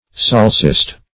solecist - definition of solecist - synonyms, pronunciation, spelling from Free Dictionary Search Result for " solecist" : The Collaborative International Dictionary of English v.0.48: Solecist \Sol"e*cist\, n. [Gr. ???.] One who commits a solecism.